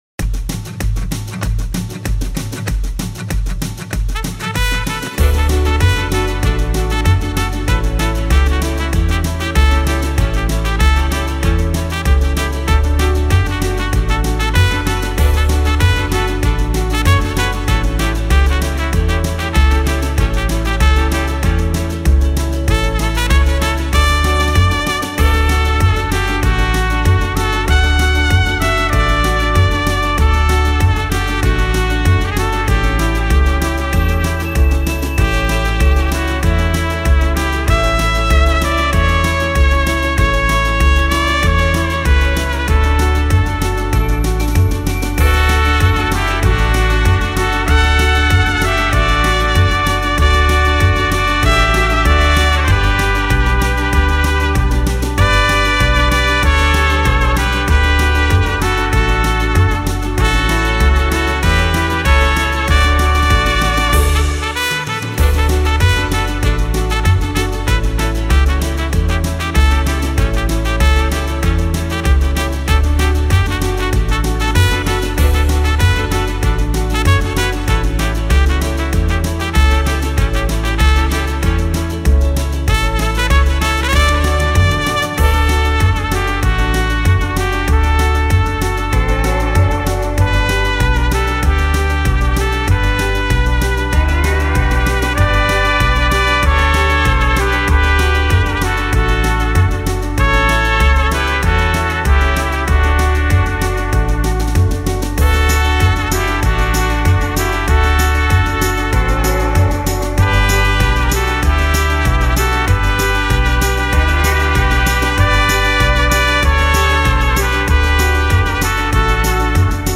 ترومپت یک ساز بادی برنجی میباشد که قدمت بسیار طولانی دارد.
فقط با ارتعاش لب ها میتوان صدای زیبای این ساز را درآورد.
صدای ساز ترومپت:
صدای-ساز-ترومپت.mp3